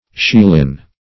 \Scheel"in\
scheelin.mp3